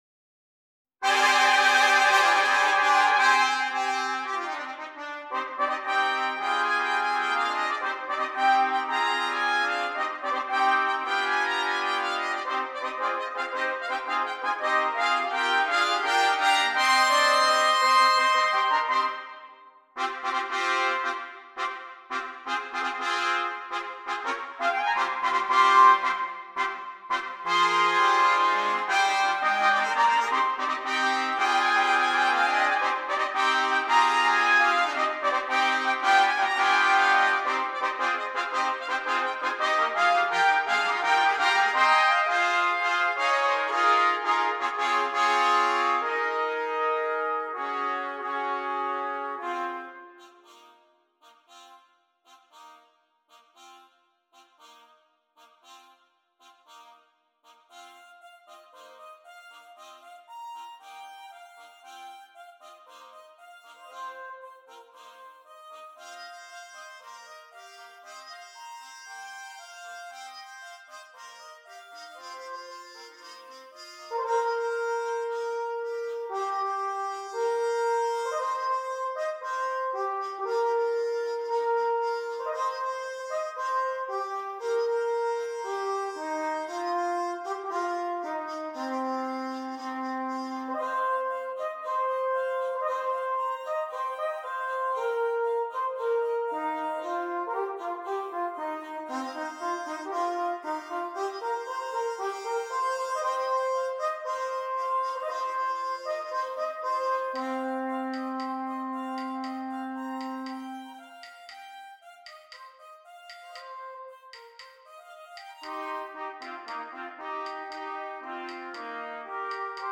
Brass Band
6 Trumpets